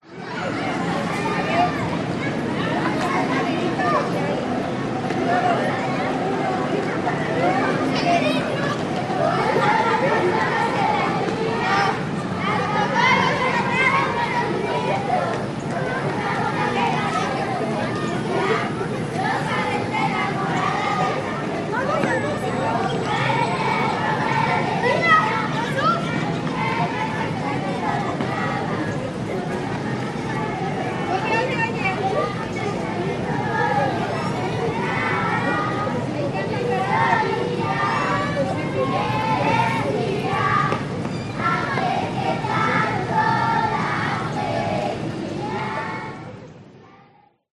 Звуки детских игр и голосов на площадке, переплетенные с шумом транспорта Мехико-Сити